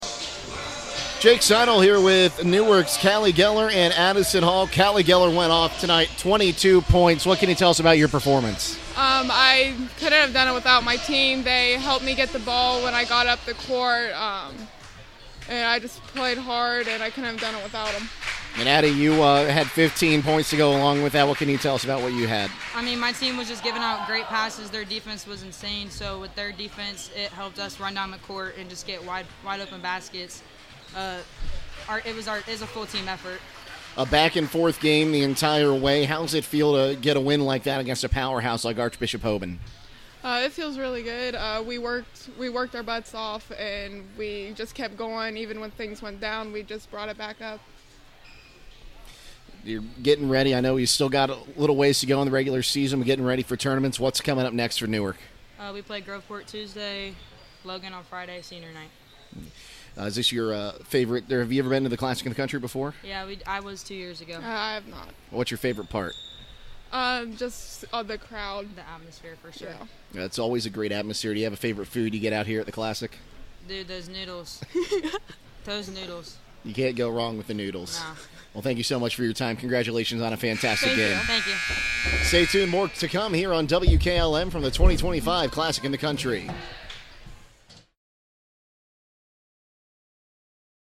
2025 Classic In the Country – Newark Player Interviews